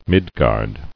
[Mid·gard]